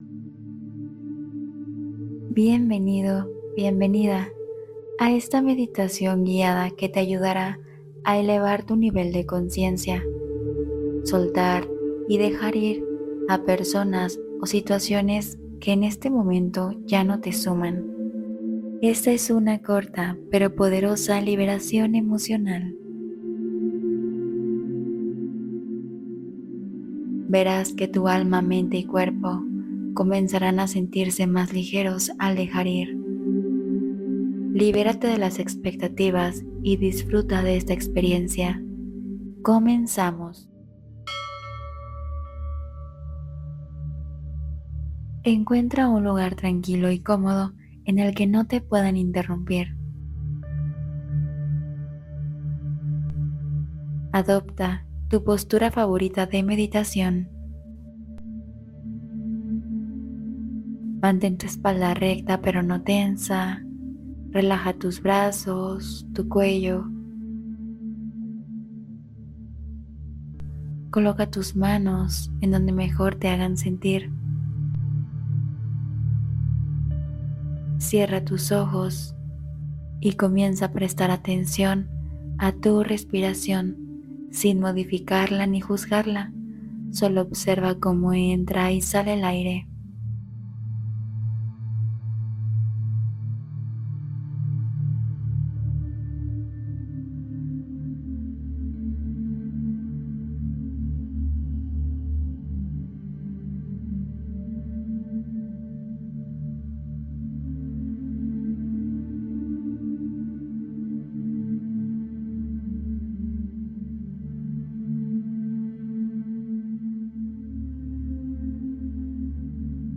Cuando la Mente se Satura: Meditación para Respirar Paz y Claridad